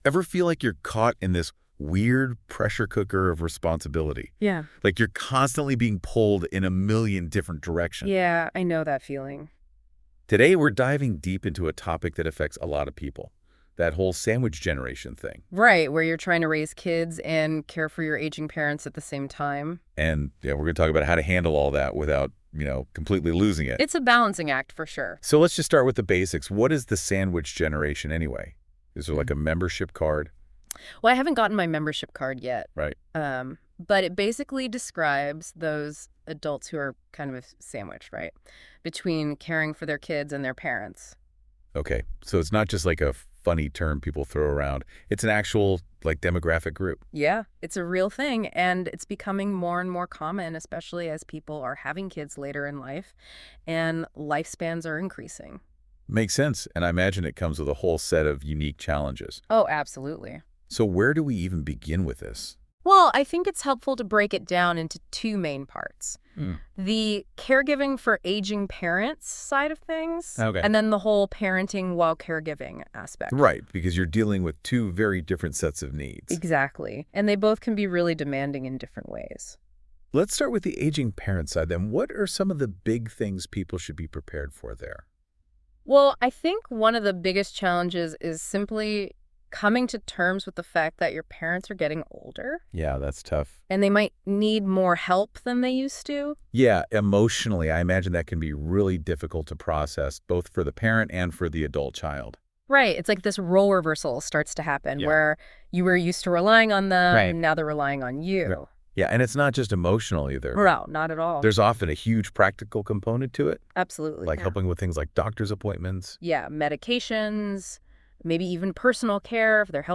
In this episode, the hosts dive into the complex realities of being part of the "sandwich generation," where individuals are tasked with raising their children while also caring for aging parents. They discuss the emotional, financial, and practical challenges that come with balancing these responsibilities and share advice on how to manage it all without feeling overwhelmed.